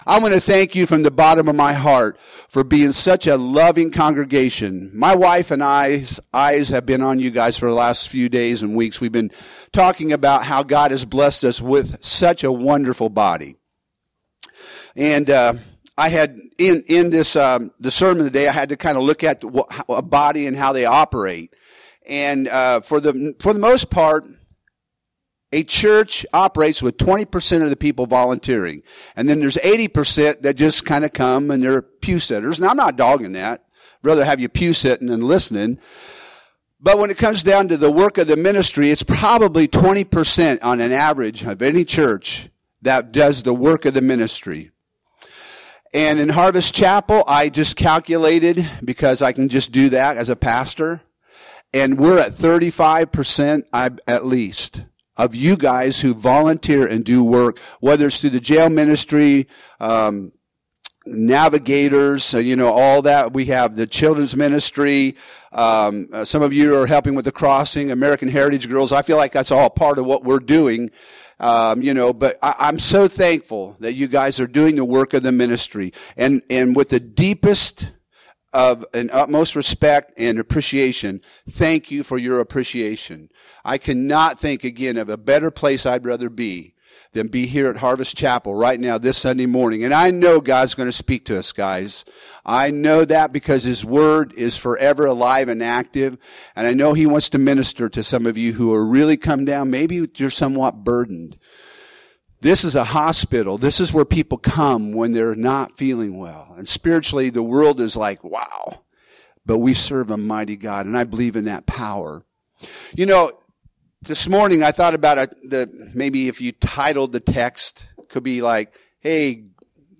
Home › Sermons › Ephesians 4:7~16